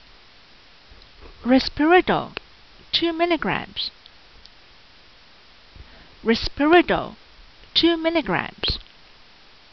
Pronunciation[edit]